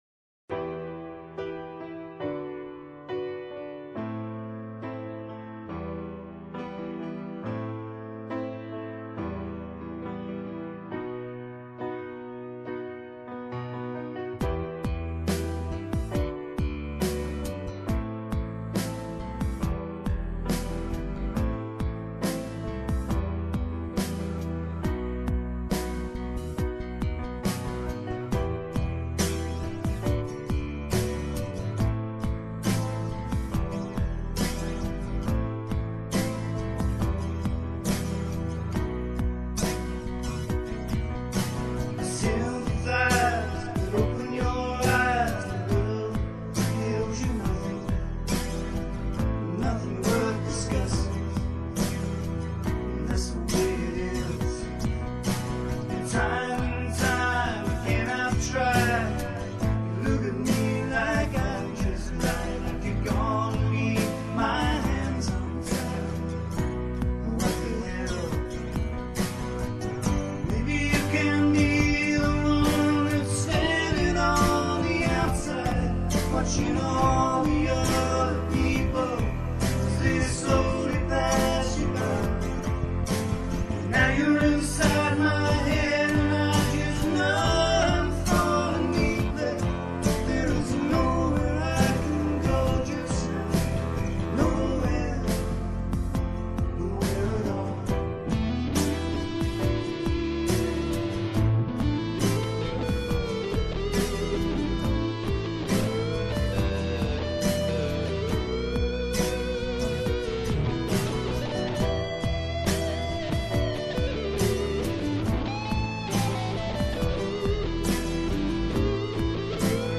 dance/electronic
recorded at his home studio using Cubase
Punk
Rock & Roll